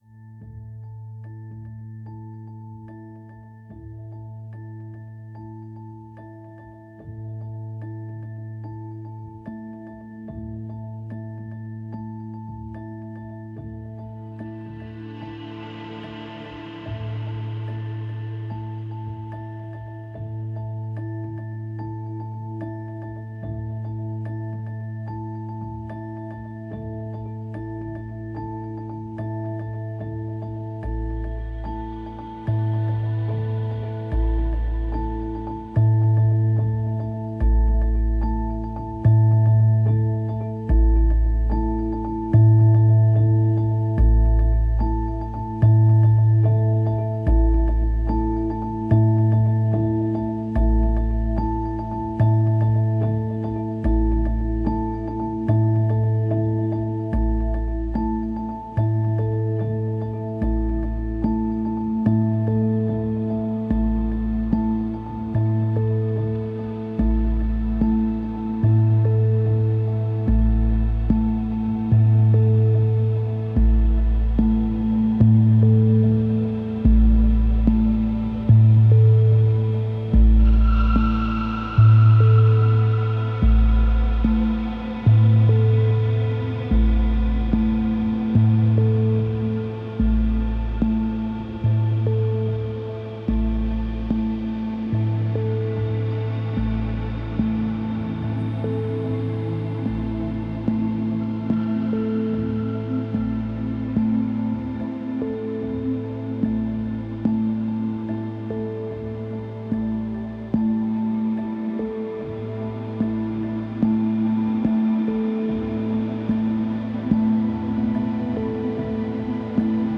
A melodious softening of an atmospheric pulse.